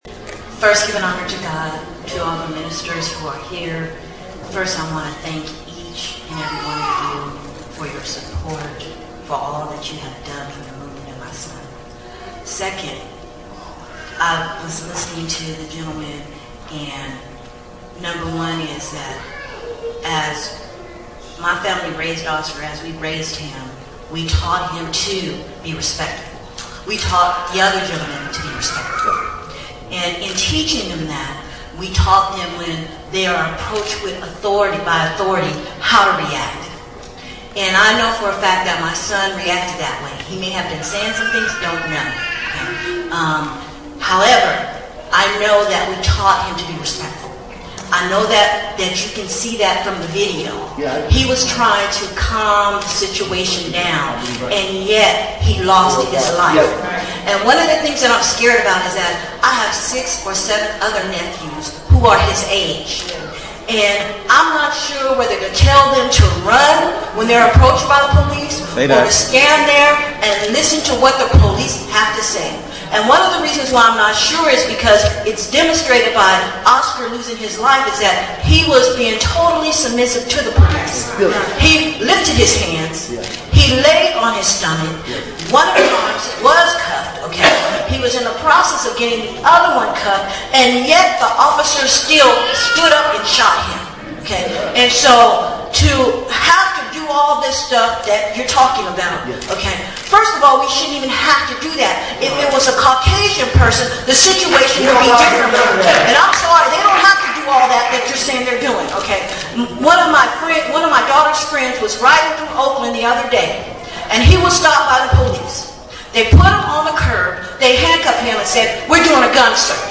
Town Hall Meetings
Olivet Institutional Missionary Baptist Church